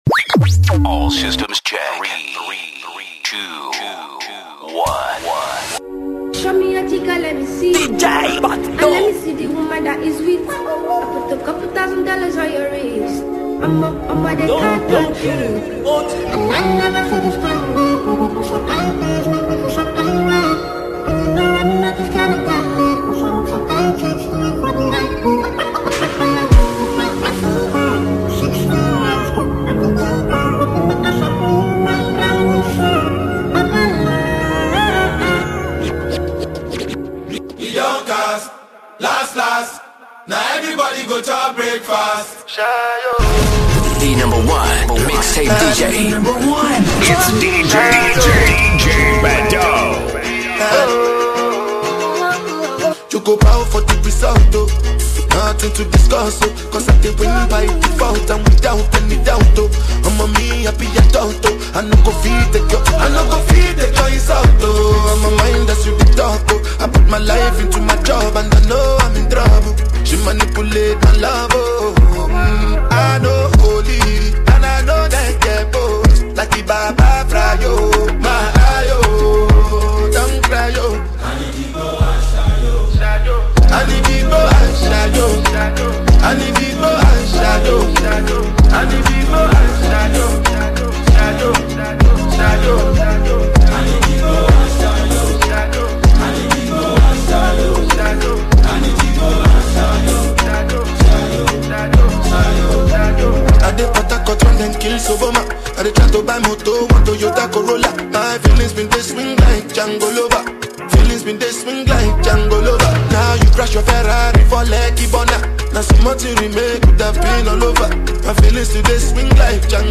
Nigerian Disc Jockey
Experience the joy of dance and rhythm